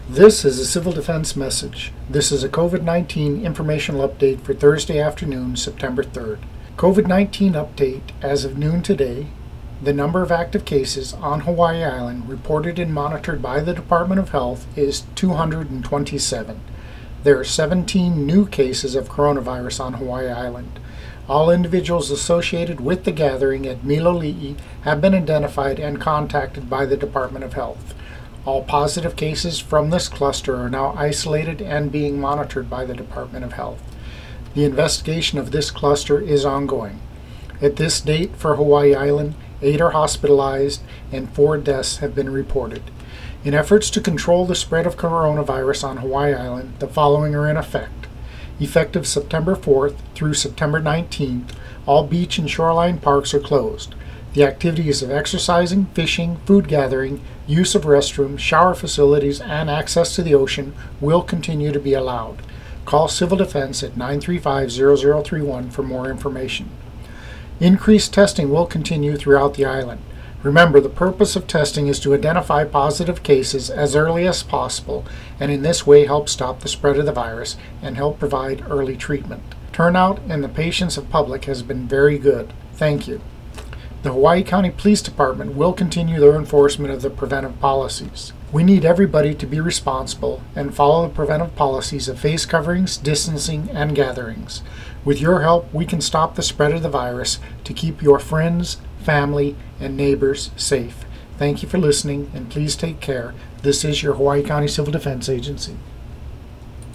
Hawaiʻi County Civil Defense issued a second radio message on Thursday afternoon, reflecting the new State numbers.